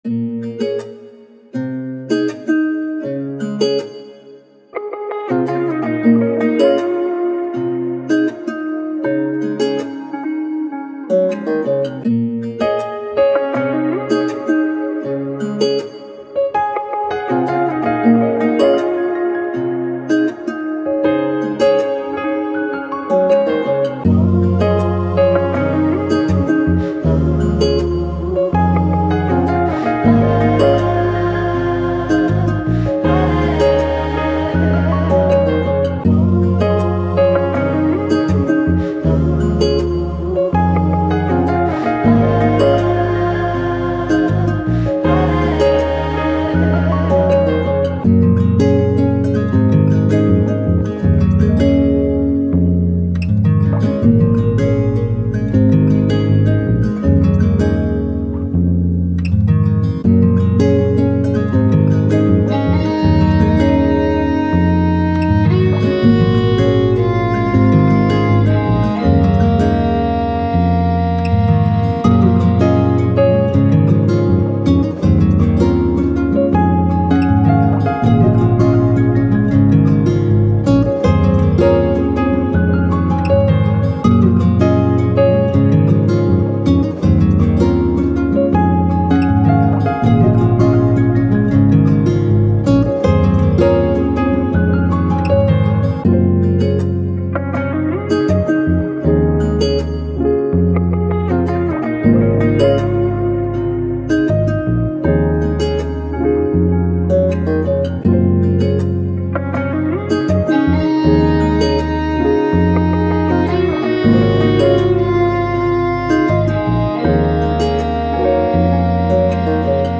Ambient, Soundtrack, Downtempo, Emotive, Lofi, Thoughtful